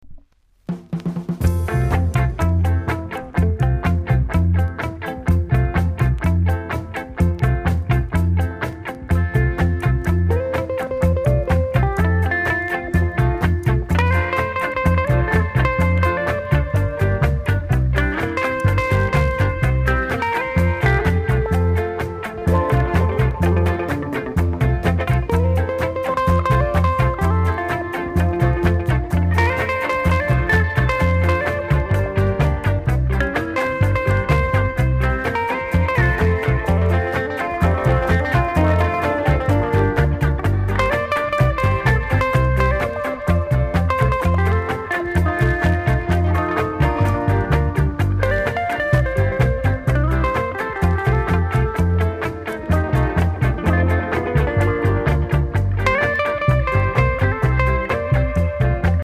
※若干音が濁り気味です。ほか小さなチリ、パチノイズが少しあります。